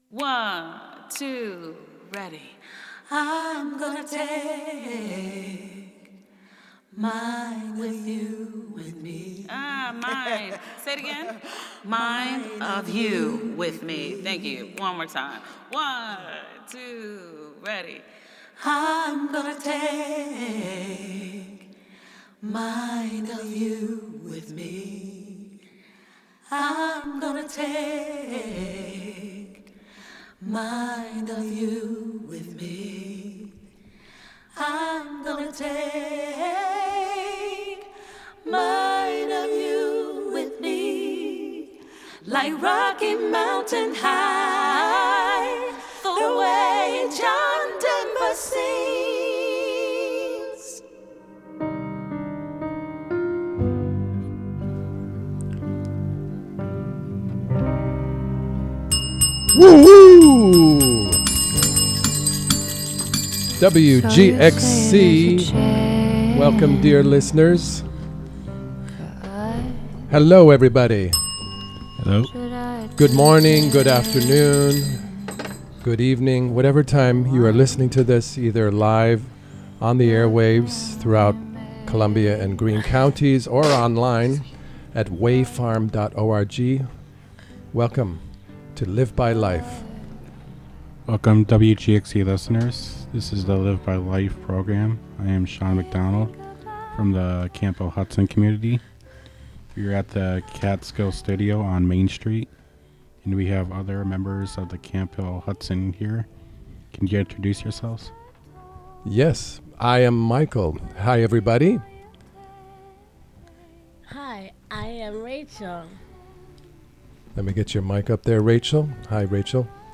Members of the Camphill Hudson Radio Group from Camphill Hudson make their own radio.